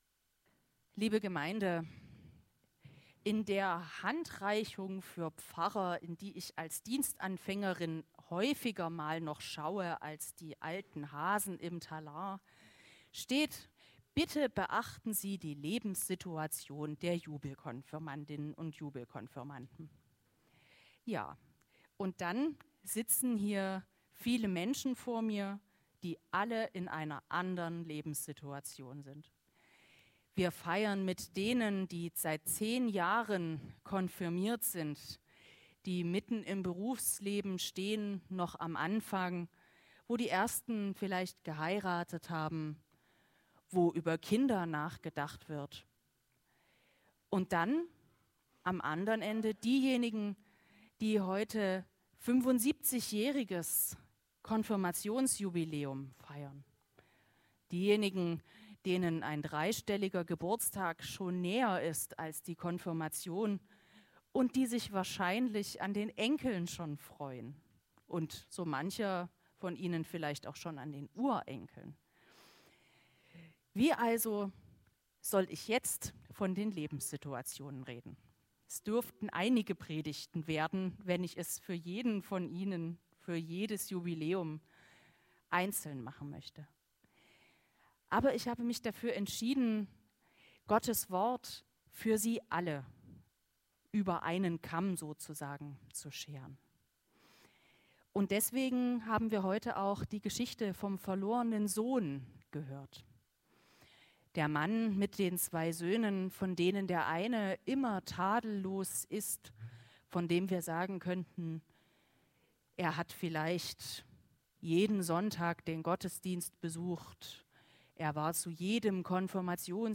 Passage: Lukas 15, 11-32 Gottesdienstart: Jubelkonfirmation